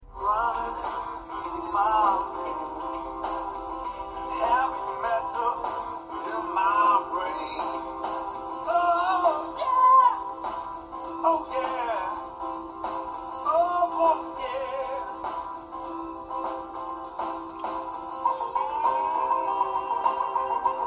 正確な音質の判断にはなりませんが、PCディスプレイのスピーカーとの違いは十分伝わると思います。
【曲1】PCディスプレイ内蔵スピーカー
フリー音楽